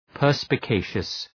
Shkrimi fonetik{,pɜ:rspə’keıʃəs}